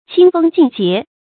清风劲节 qīng fēng jìn jié
清风劲节发音
成语注音ㄑㄧㄥ ㄈㄥ ㄐㄧㄣˋ ㄐㄧㄝ ˊ